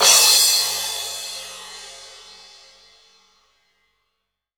Index of /90_sSampleCDs/AKAI S6000 CD-ROM - Volume 3/Crash_Cymbal1/FX_CYMBAL
FX+MIX CYM S.WAV